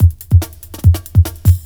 ELECTRO 06-L.wav